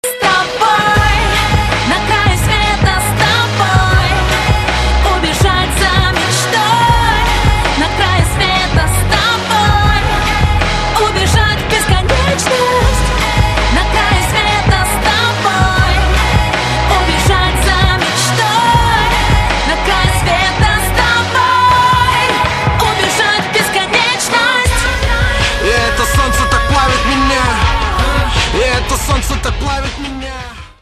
• Качество: 128, Stereo
красивые
женский вокал
мелодичные
dance
club
красивый женский голос
звонкие
vocal
приятная музыка